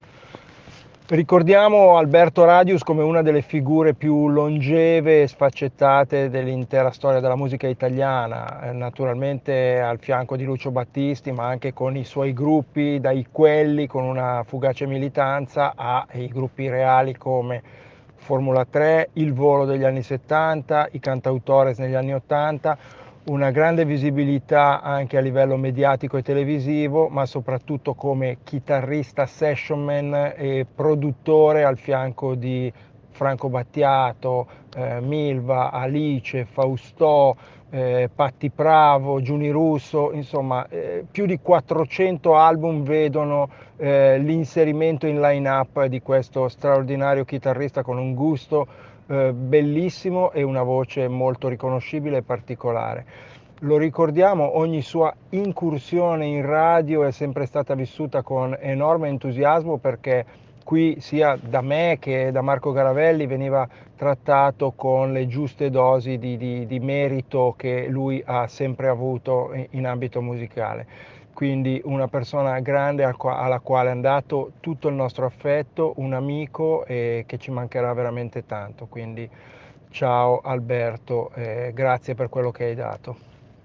Una Telefonica non pianificata, nata da curiosità personali.